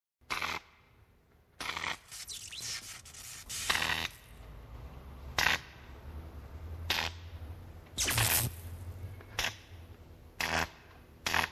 Sonneries » Sons - Effets Sonores » bruitage electricité